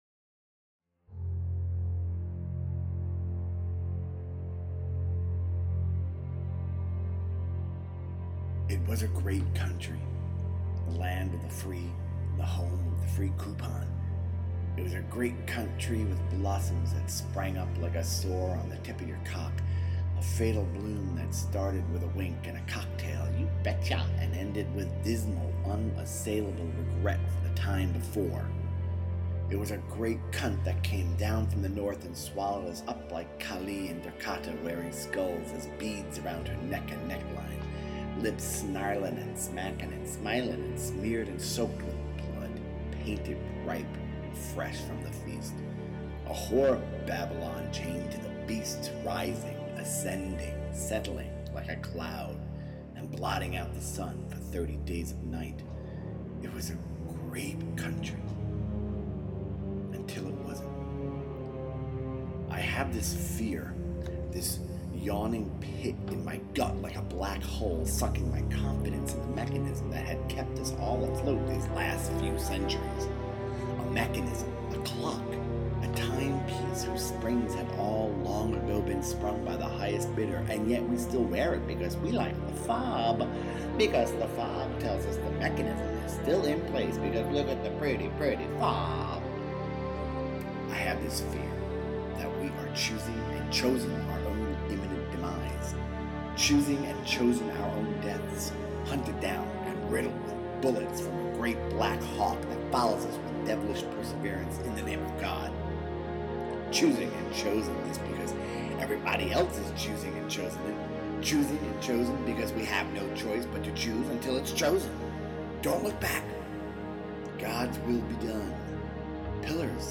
This is a work-in-progress, the opening of a larger vaudeville about the end of the world. I wrote it, recorded it on my computer, sent it over to the UK where my brilliant partner Will Todd composed the underscoring over night.
apoc-1-with-music-as-mp3.mp3